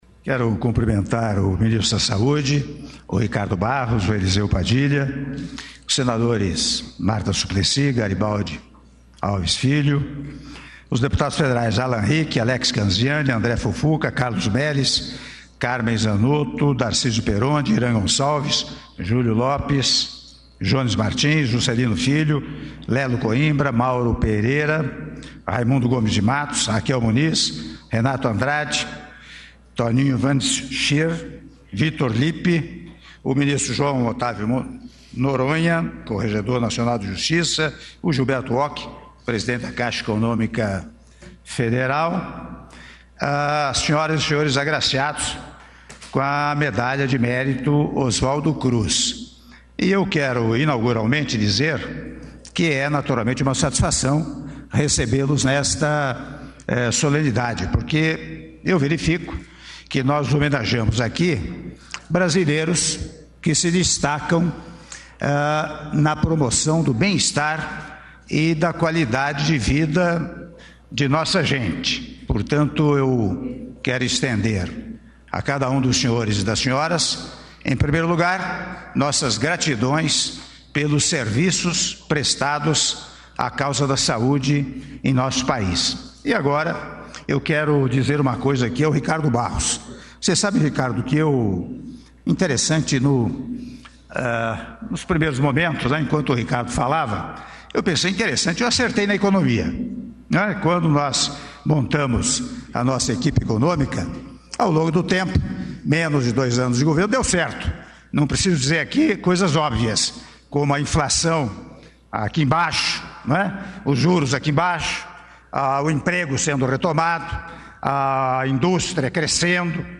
Áudio do discurso do Presidente da República, Michel Temer, durante Cerimônia de Entrega da Medalha de Mérito Oswaldo Cruz - (07min40s) - Brasília/DF